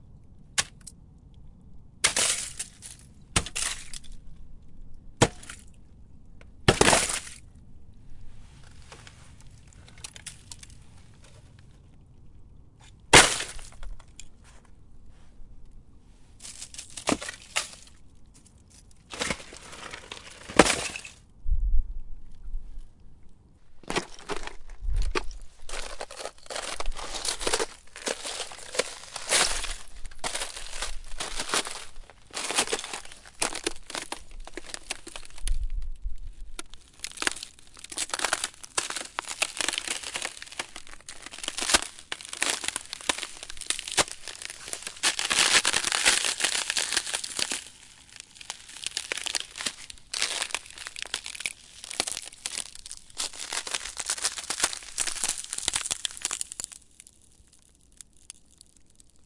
描述：粉碎冰块并在上面行走，用zoom h4n
Tag: 场记录 家庭录音 晶体 挤压 冷冻 冬季